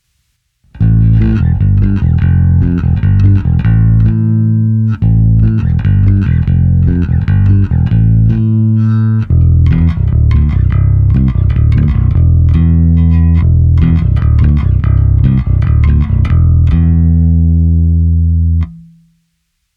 Nahrávky jsou bez simulace aparátu, nicméně jsou prohnané skrz kompresor a lampový preamp a použil jsem individuálně pro každou nahrávku i korekce přímo na baskytaře. Použité struny jsou nové niklové pětačtyřicítky Elixir.
Cívky 1 a 2 – zvuk ala '60 Jazz Bass